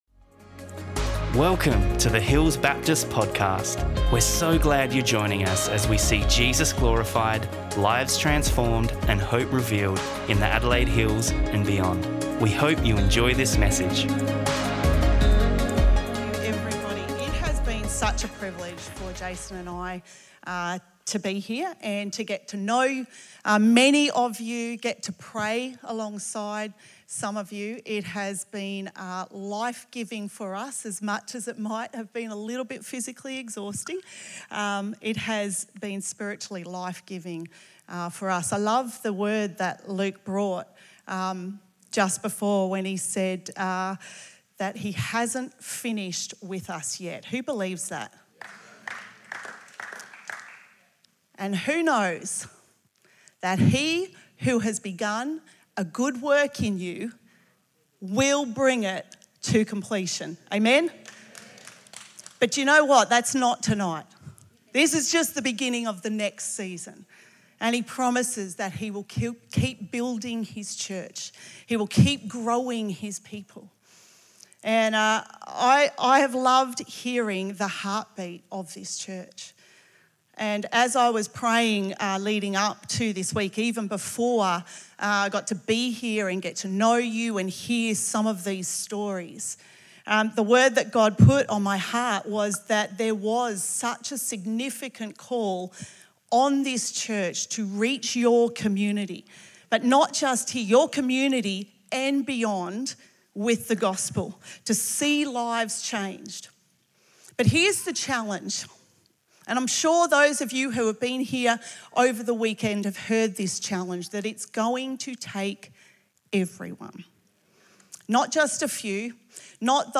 Hills Baptist Church